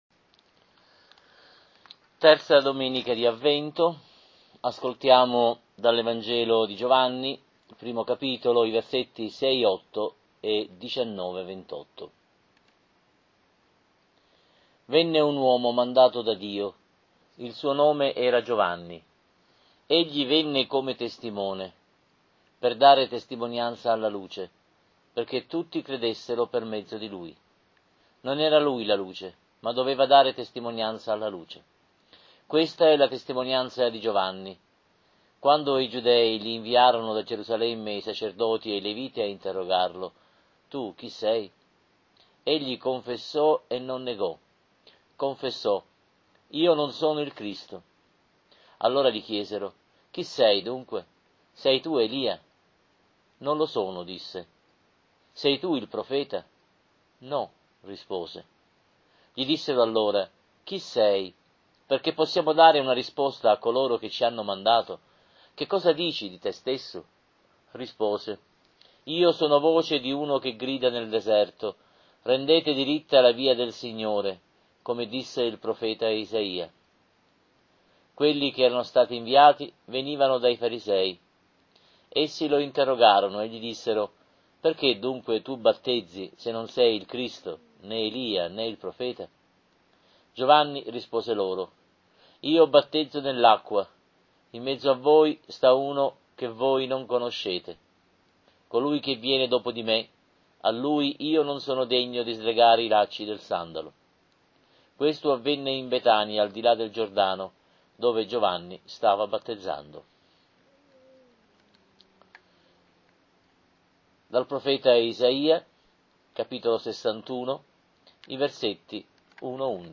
Lectio divina Domenica «DELL’AGNELLO DI DIO», III di Avvento, Anno B - Abbazia di Pulsano.